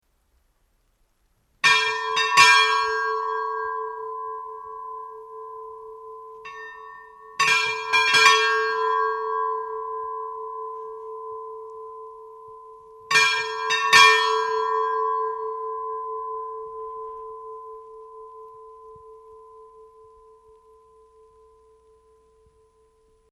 Hrunakirkja var byggð árin 1865-1866. Í henni eru tvær kirkjuklukkur sem komu í kirkjuna árin 1929 og 1947.
hrunakirkja_badar.mp3